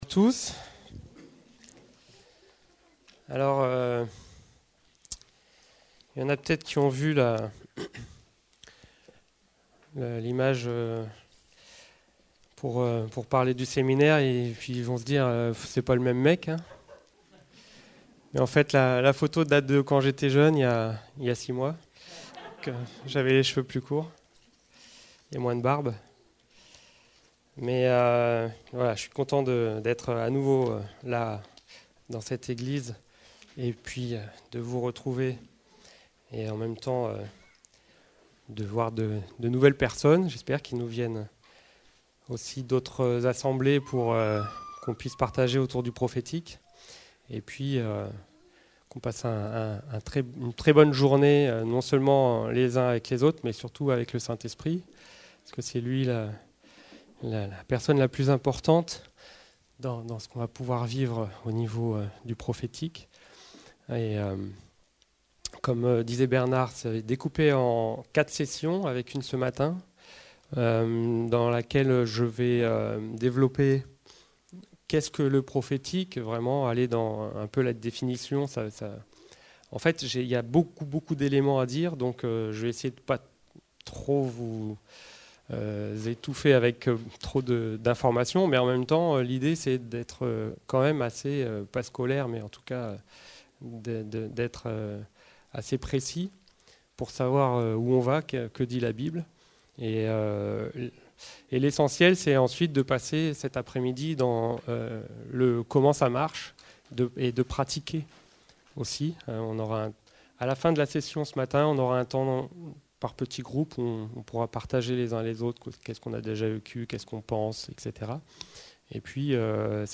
Type De Service: Formation Thèmes: Dons spirituels , Prophétique , Vie chrétienne personnelle , Vie d'église « Le temps des naziréens Séminaire prophétique